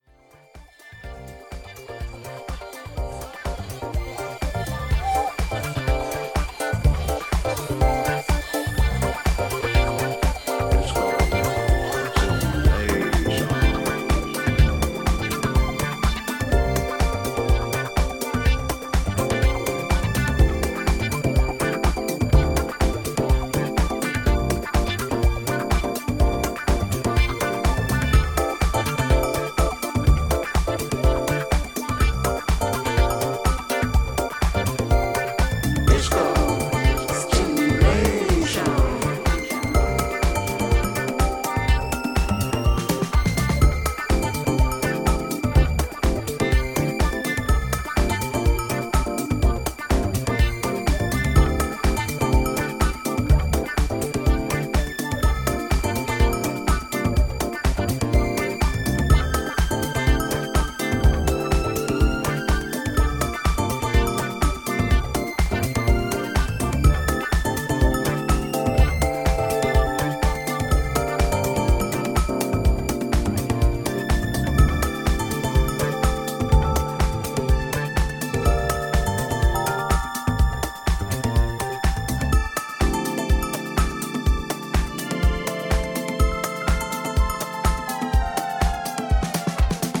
ディスコのコズミックな側面にインスパイアされているという楽曲を展開